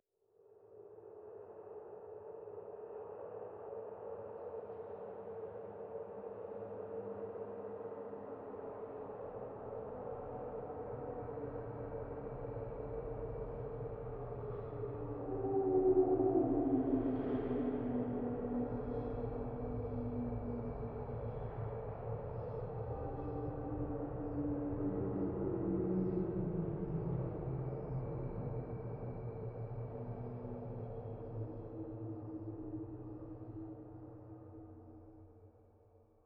05_诡异通用_2.wav